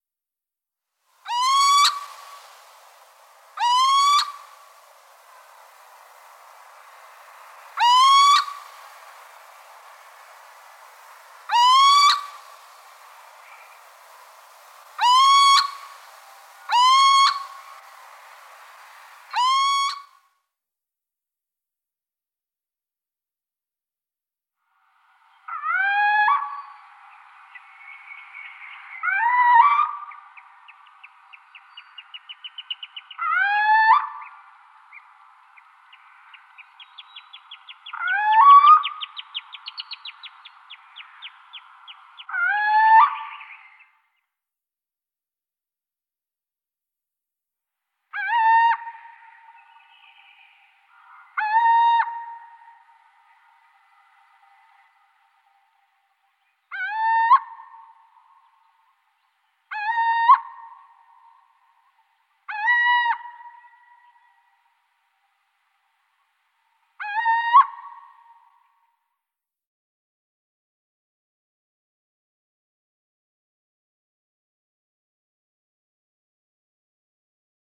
Steenuil
De steenuil maakt verschillende geluiden.
steenuilzang.mp3